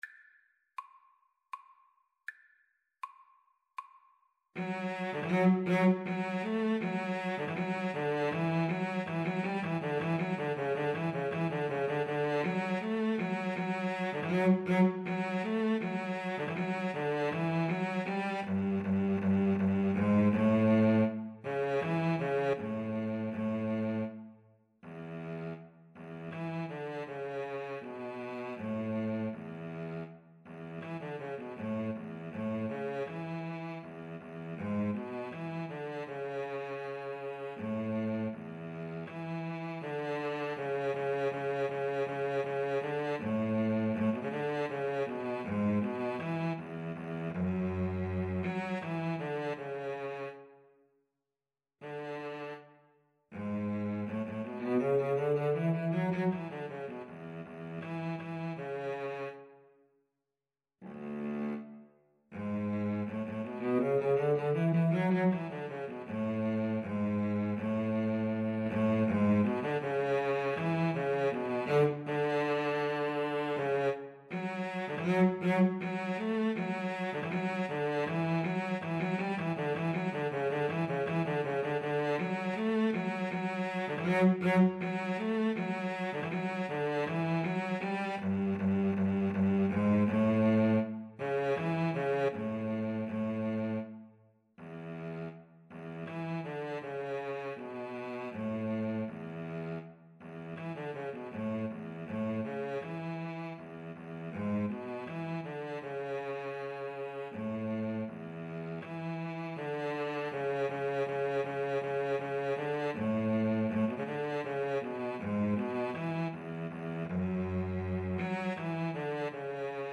3/4 (View more 3/4 Music)
Cello Duet  (View more Advanced Cello Duet Music)
Classical (View more Classical Cello Duet Music)